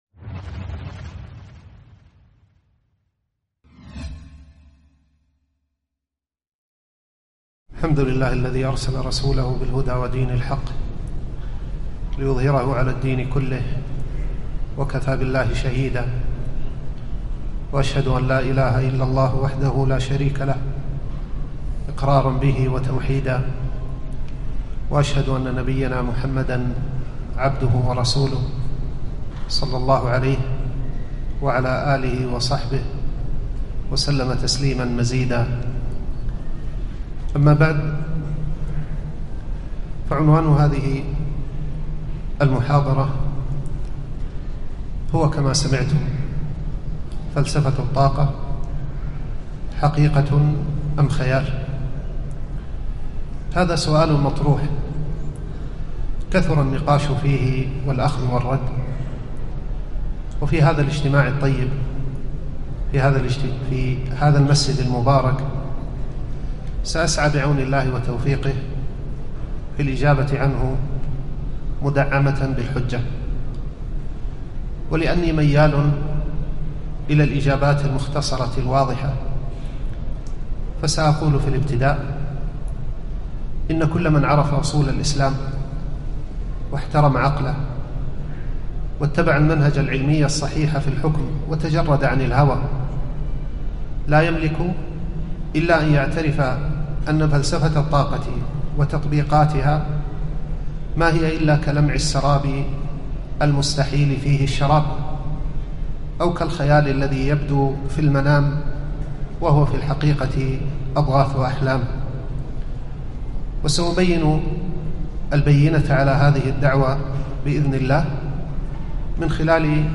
محاضرة قيمة - فلسفة الطاقة .. حقيقة أم خيال؟